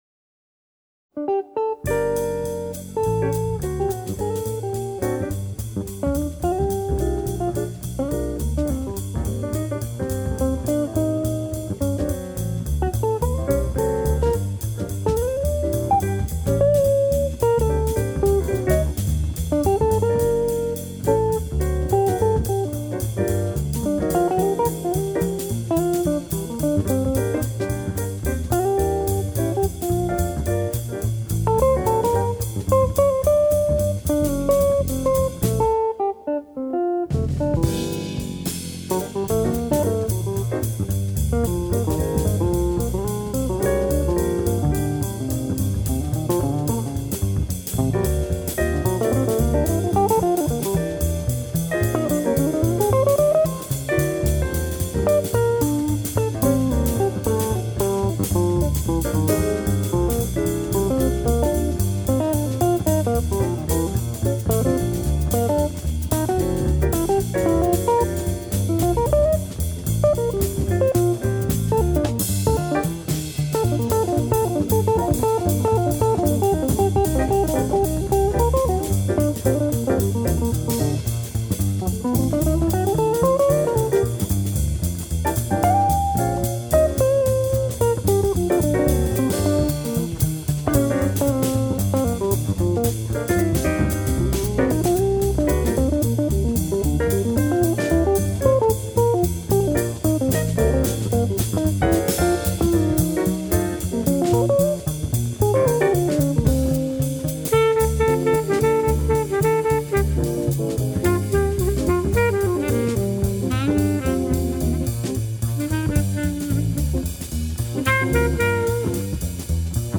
He was more about unusual chord clusters and melody.
sax
trumpet
drums